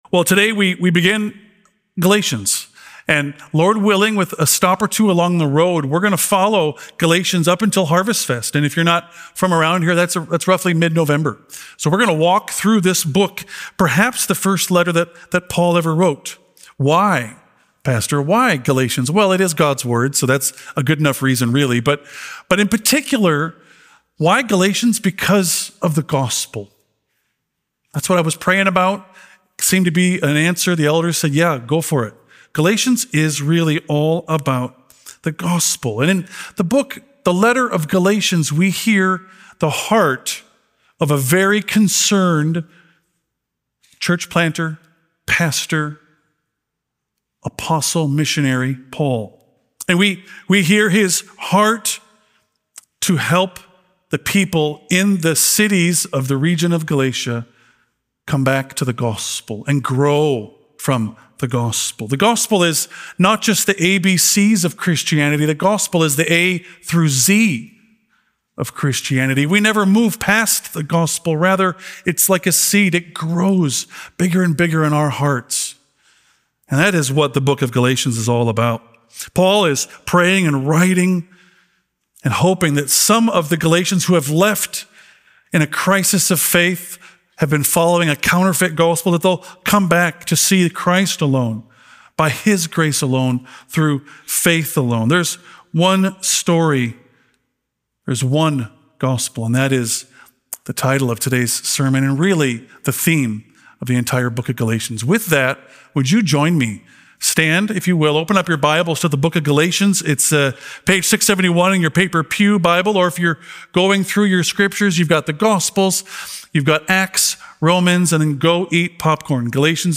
A message from the series "Galatians." This week, we launch our journey through the book of Galatians—a passionate, pastoral, and theologically rich letter from the Apostle Paul that centers on one truth: There is only one gospel.